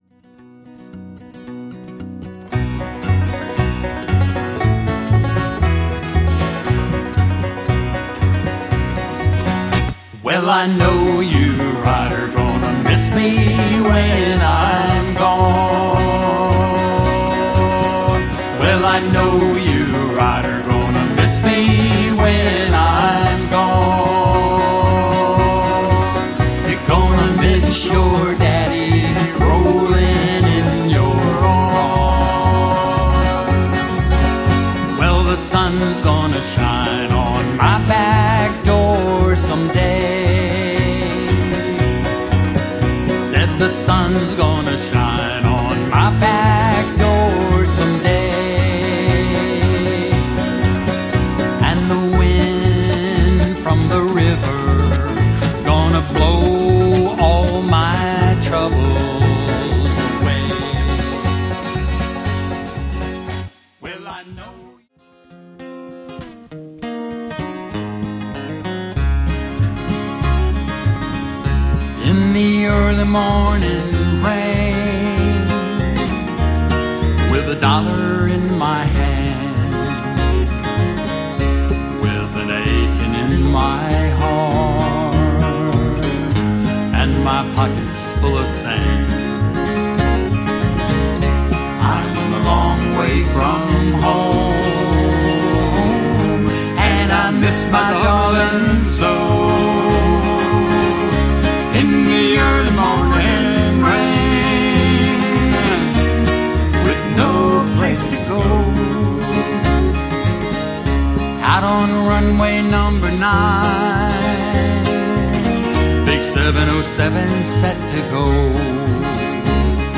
Scots/Irish folk music… rearranged by the sensational Kingston Trio in the 60's..
Since Denver grew up playing Kingston, O/T merged Denver's hits with the Kingston 'banjo/folk music.